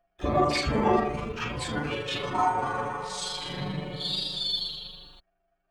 Processing: granulated, 8 voi + KS = 203, F=980, + oct. lower, 3rd higher 0:1, then 2:1